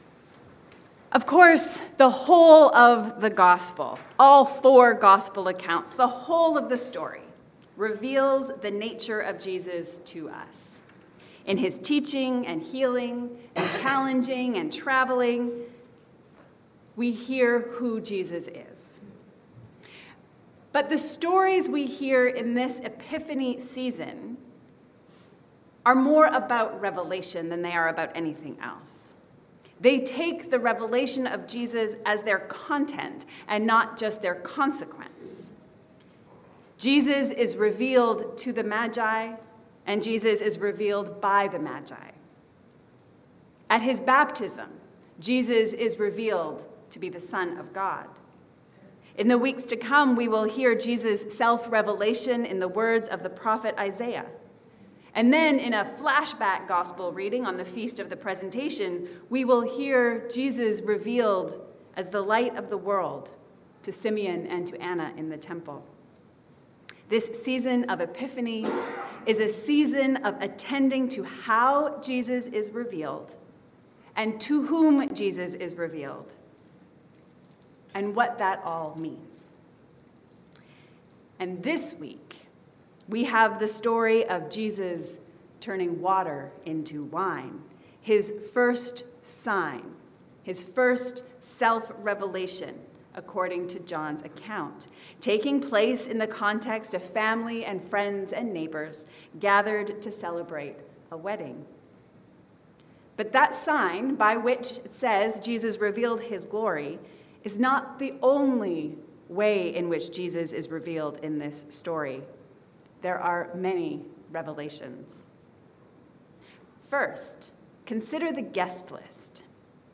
It takes a team: Jesus’ first sign. A sermon on John 2:2-11.